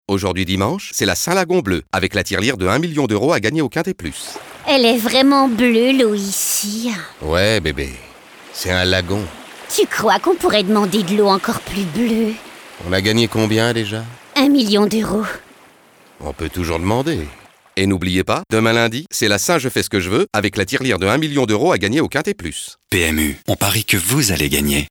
Nunuche, godiche, souriante https